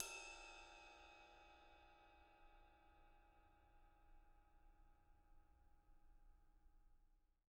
R_B Ride 02 - Room.wav